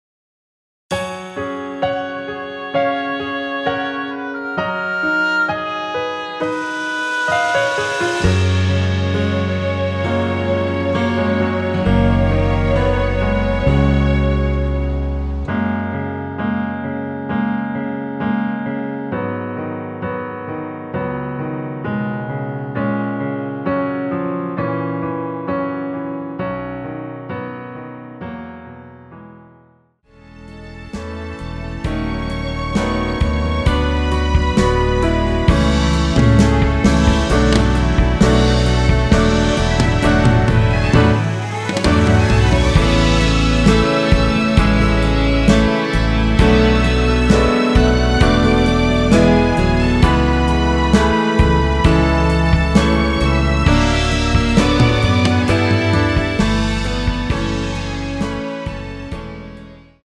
원키에서(-4)내린 1절 + 후렴으로 편곡한 MR 입니다.(미리듣기및 가사 참조)
F#
앞부분30초, 뒷부분30초씩 편집해서 올려 드리고 있습니다.
중간에 음이 끈어지고 다시 나오는 이유는